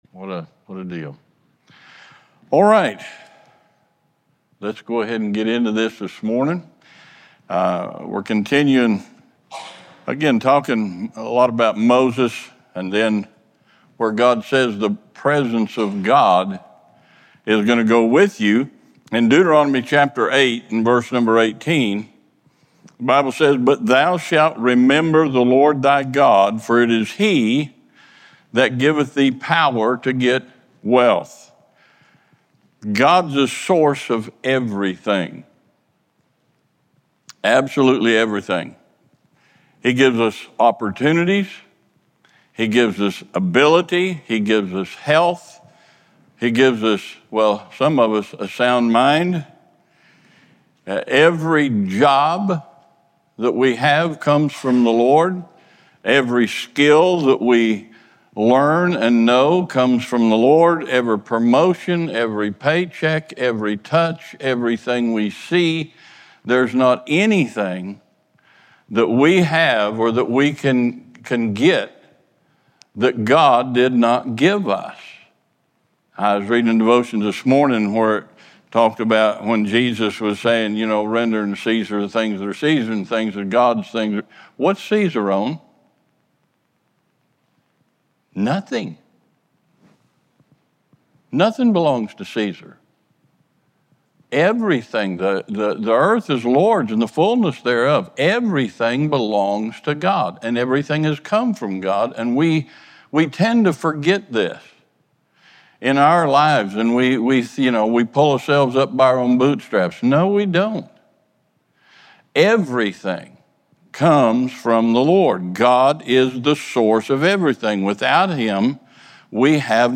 Sermons | First Baptist Church
Sunday School